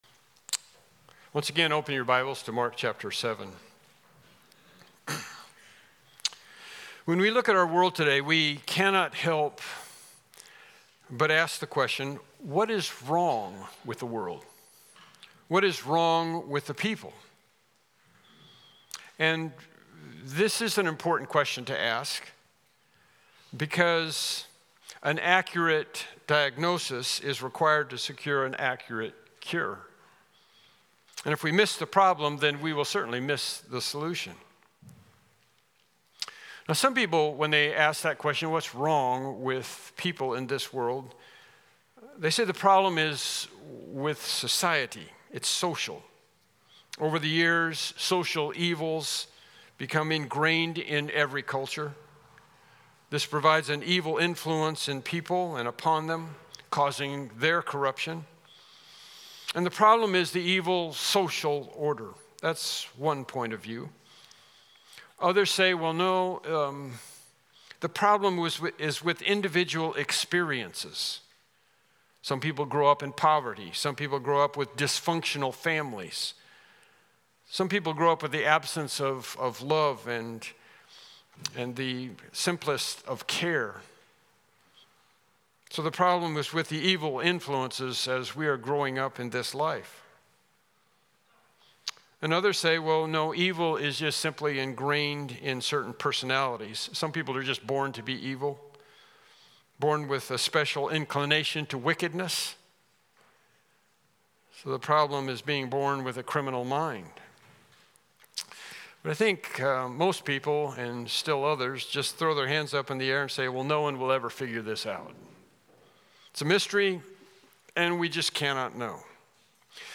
Passage: Mark 7:17-23 Service Type: Morning Worship Service « Lesson 15